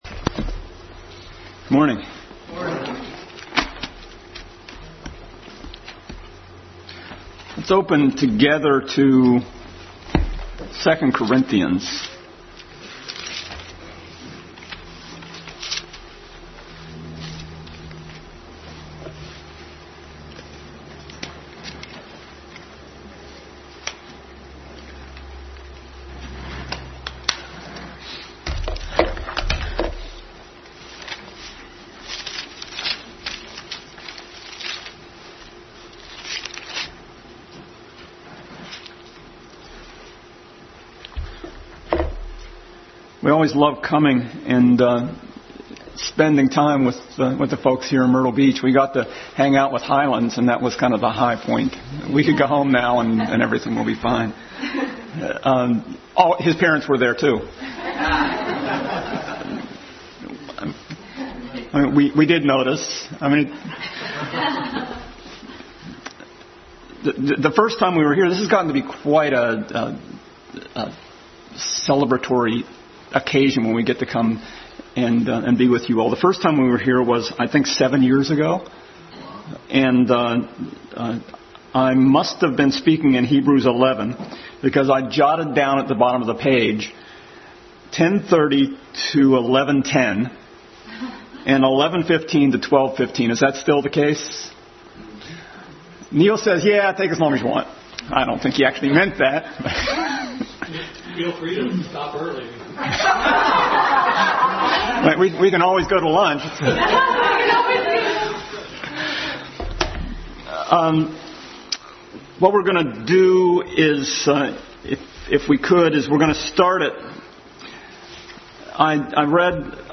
Adult Sunday School Class continued study in 2 Corinthians.